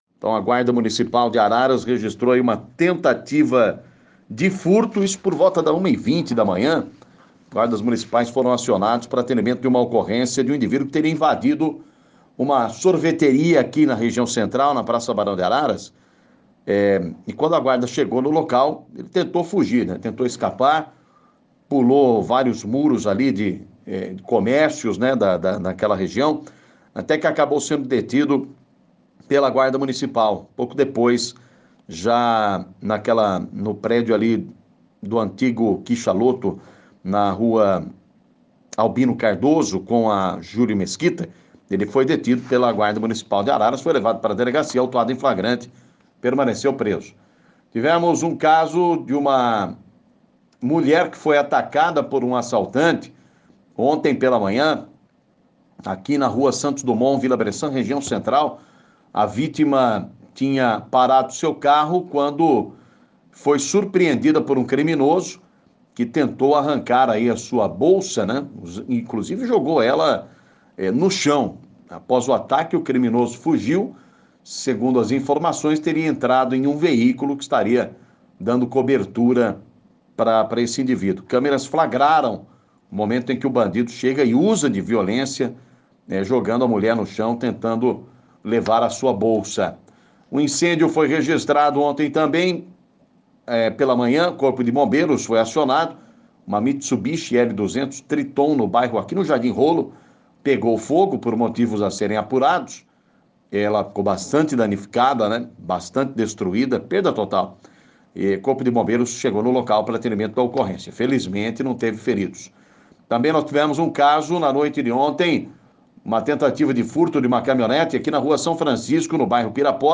Polícia